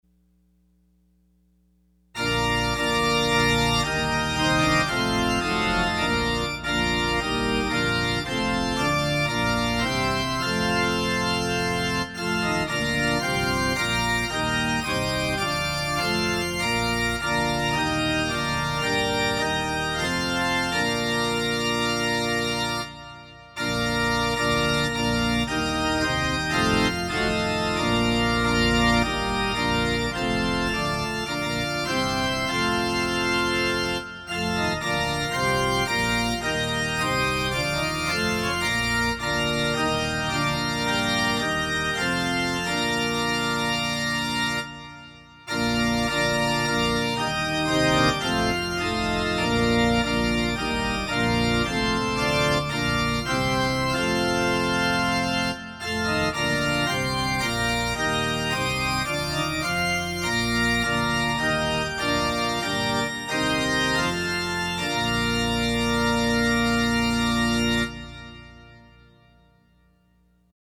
Opening Hymn – Before the Lord’s eternal throne #391